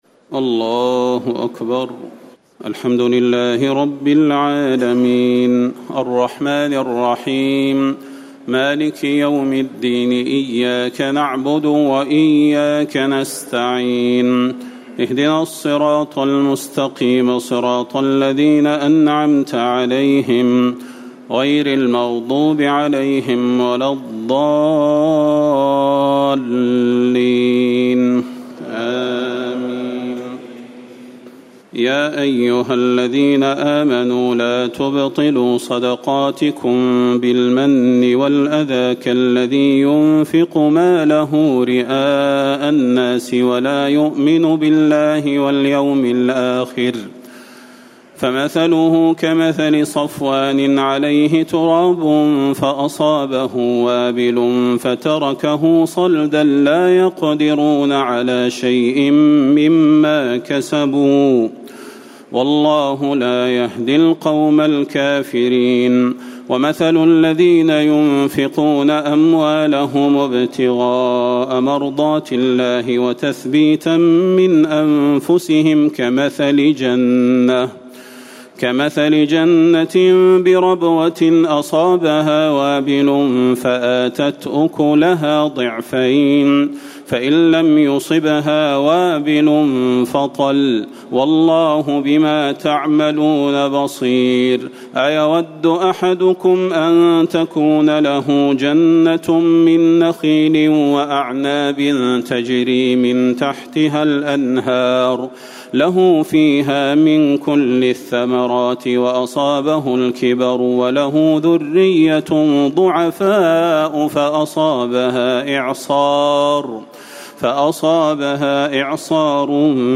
تهجد ليلة 23 رمضان 1439هـ من سورتي البقرة (264-286) و آل عمران (1-92) Tahajjud 23 st night Ramadan 1439H from Surah Al-Baqara and Aal-i-Imraan > تراويح الحرم النبوي عام 1439 🕌 > التراويح - تلاوات الحرمين